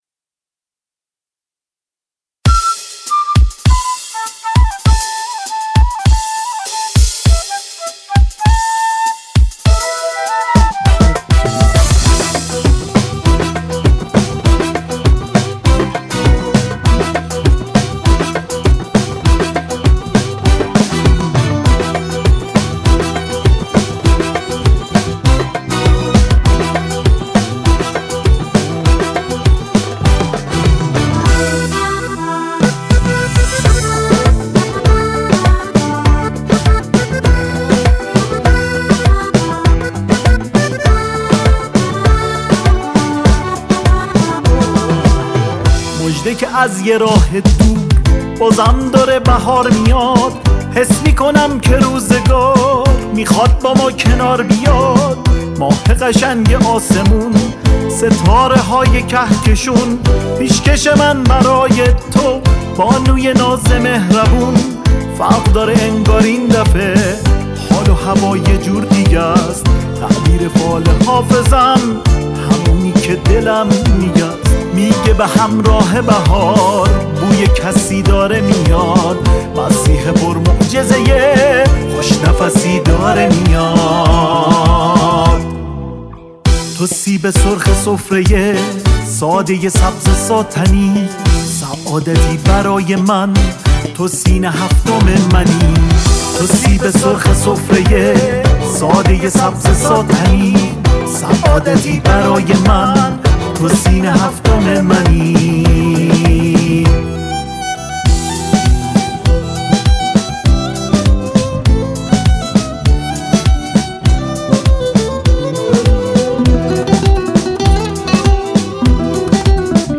عوامل این قطعه شامل گیتار
گیتار باس
ویولن